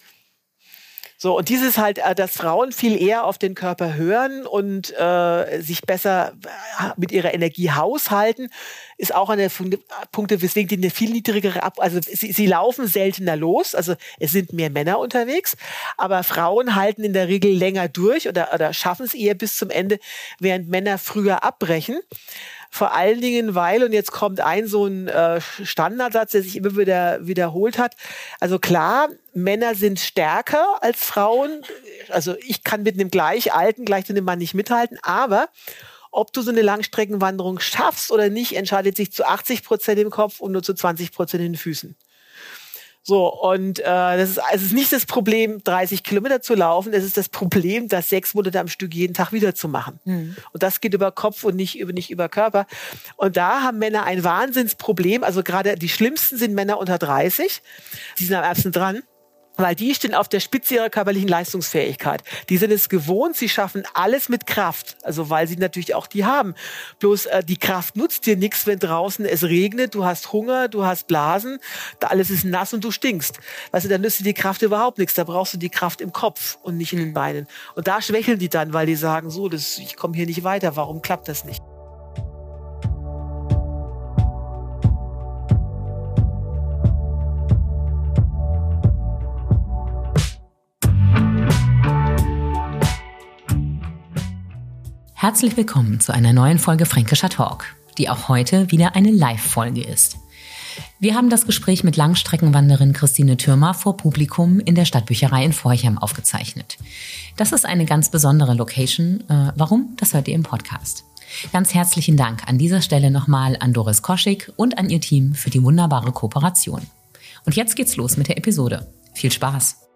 Sie ist 70.000 Kilometer gewandert, isst vier Tafeln Schokolade pro Tag und hat mehr Angst vor Zecken als vor Klapperschlangen (und Männern): Christine Thürmer. In diesem Live-Podcast erzählt sie, warum sie ohne Unterhose wandert, von Penisfischen als Snack und sie zerreißt das Wander-Outfit von Hos...